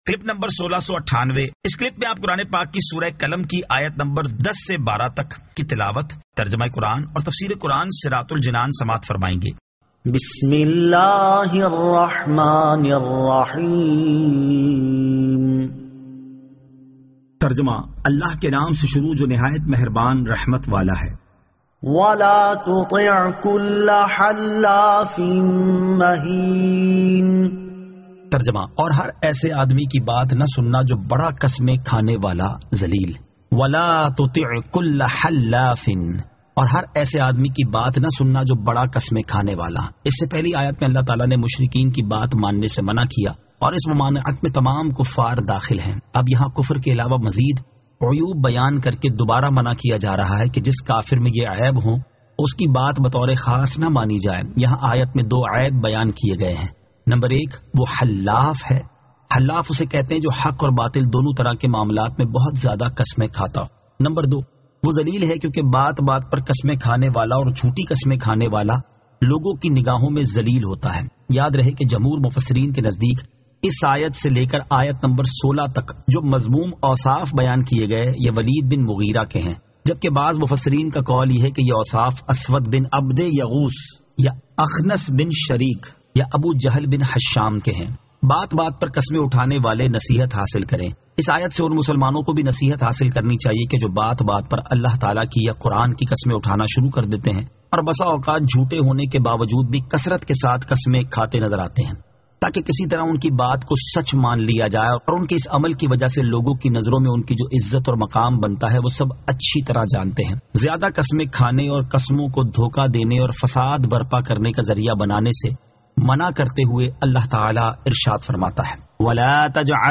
Surah Al-Qalam 10 To 12 Tilawat , Tarjama , Tafseer